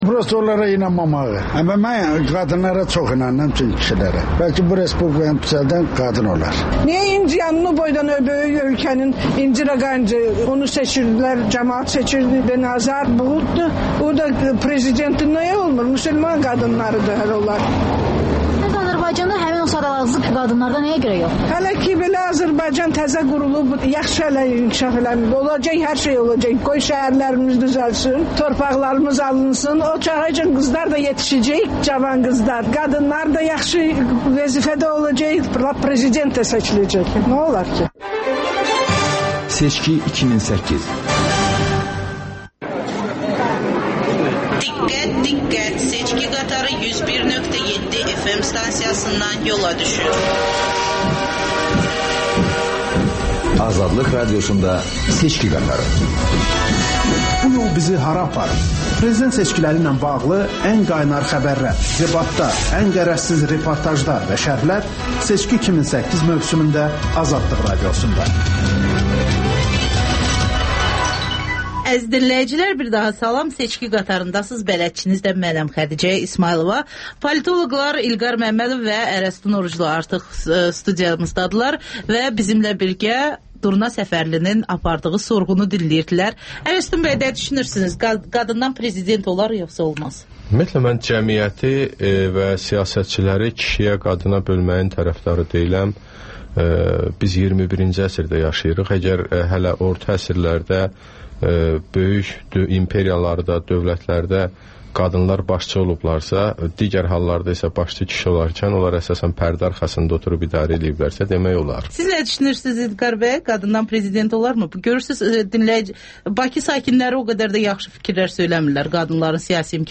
Müxbirlərimizin həftə ərzində hazırladıqları ən yaxşı reportajlardan ibarət paket (Təkrar)